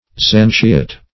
Zantiot \Zan"ti*ot\, n. A native or inhabitant of Zante, one of the Ionian Islands.